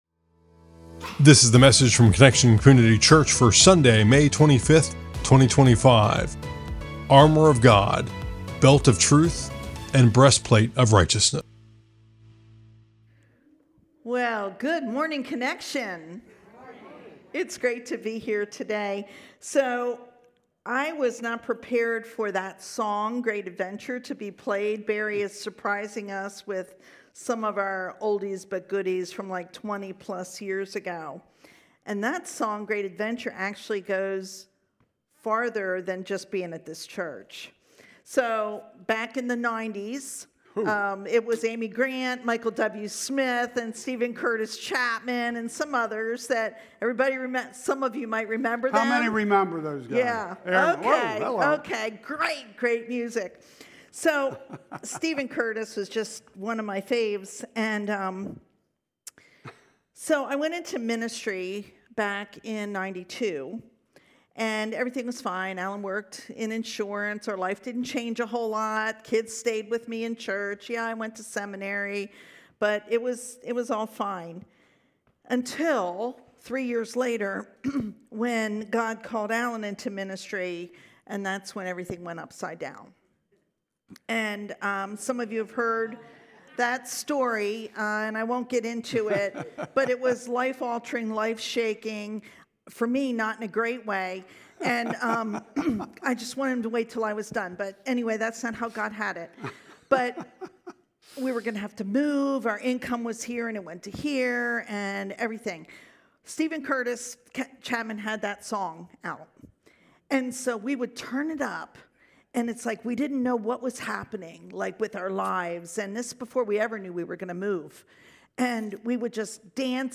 1 Optimizing Hormone Balance For Lasting Weight Loss: A Conversation